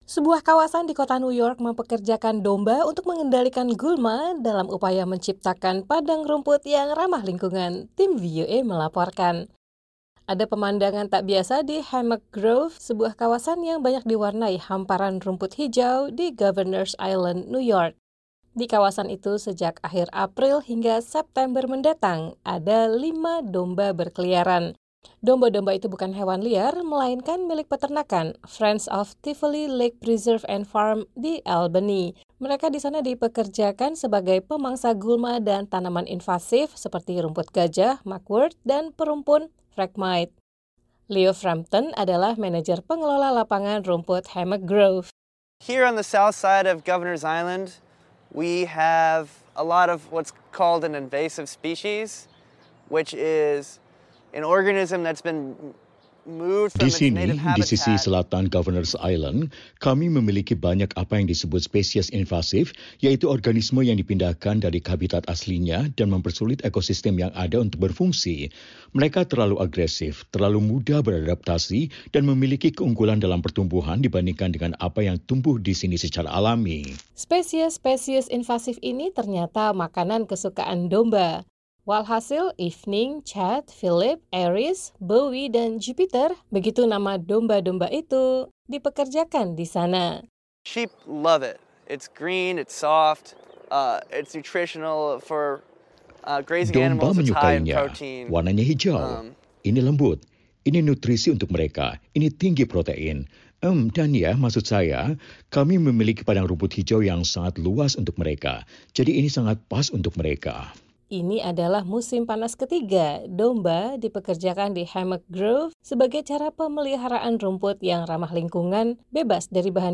Sebuah kawasan hijau di kota New York memperkerjakan domba untuk mengendalikan gulma dalam upaya menciptakan padang rumput yang ramah lingkungan. Tim VOA melaporkan.